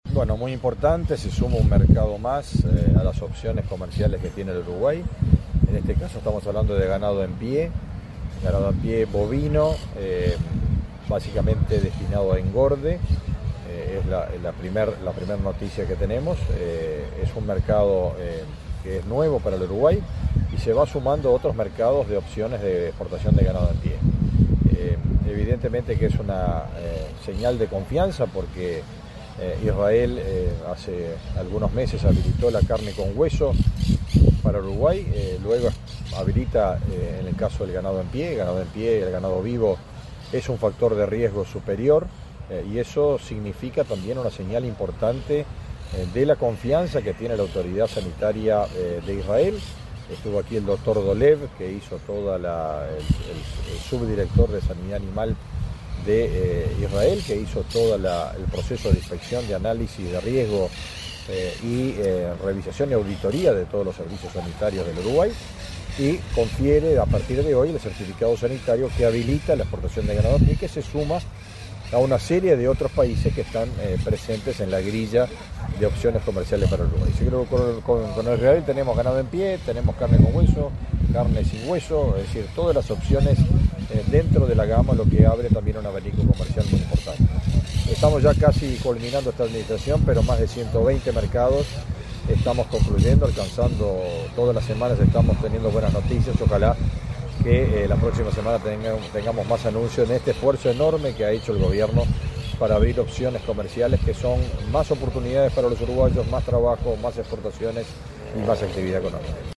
Entrevista al ministro de Ganadería, Agricultura y Pesca, Fernando Mattos